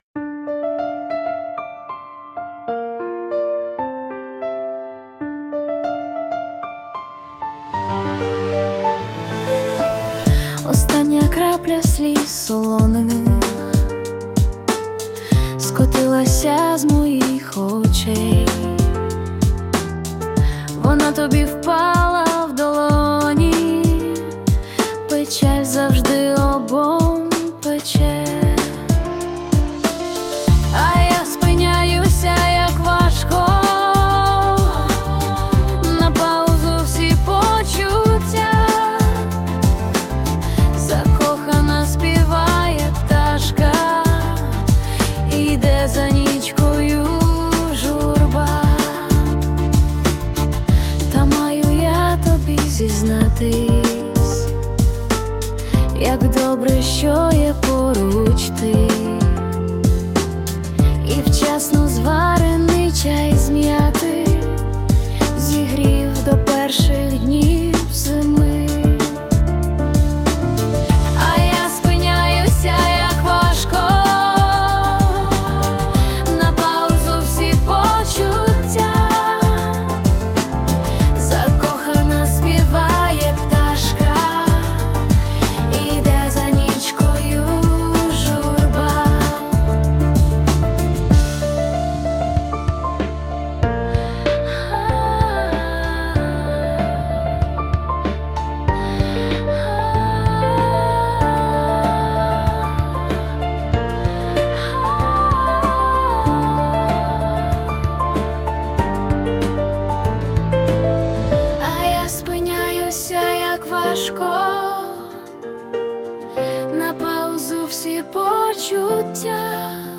Музичний супровід створено за допомогою Suno Al
СТИЛЬОВІ ЖАНРИ: Ліричний
12 12 16 Дуже гарна, душевна пісня! 021 flo12 flo12 flo12 flo36